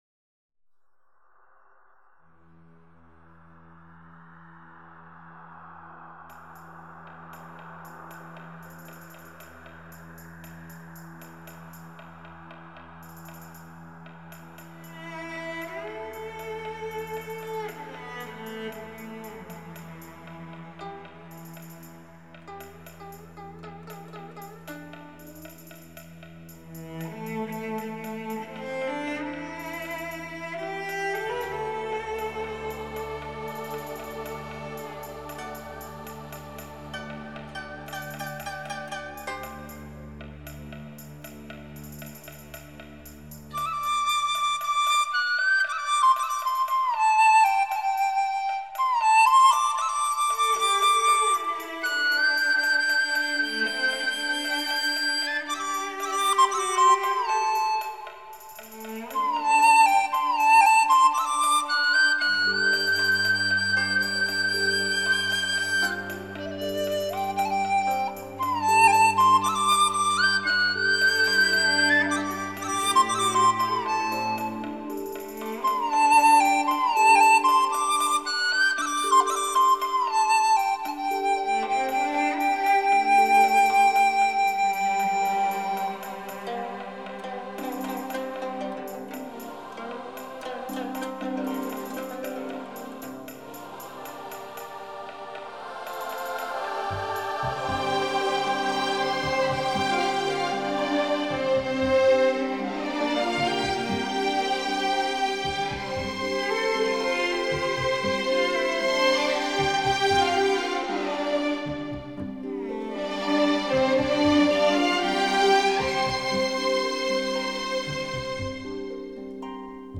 本辑是青海民歌改编的轻音乐。中国民族乐器为主奏，辅以西洋管弦乐器及电声乐器。
最新数码系统录制，堪称民乐天碟。